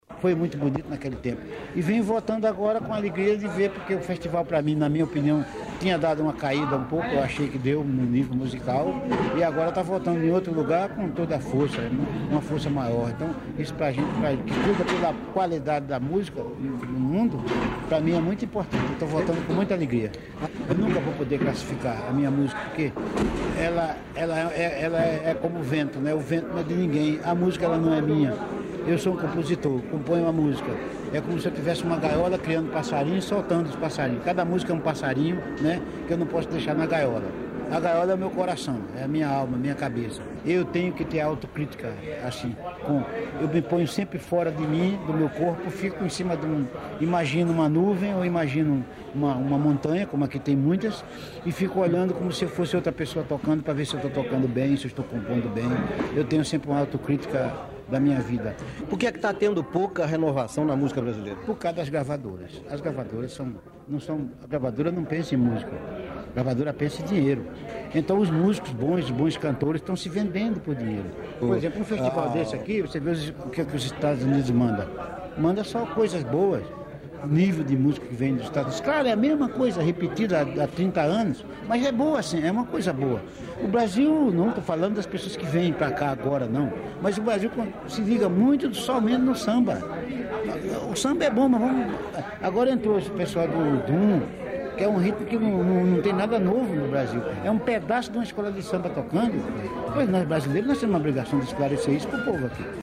Entrevista à Rádio Suíça Internacional no Festival de Montreux, em 1993.